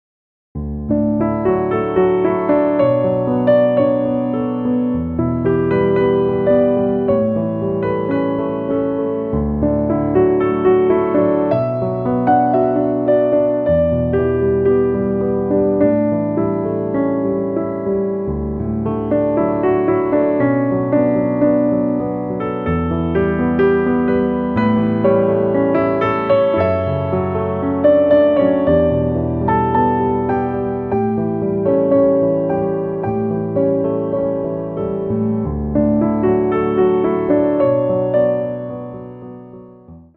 gently romantic solo piano piece with a nostalgic feel
piano music
in a new age/romantic style.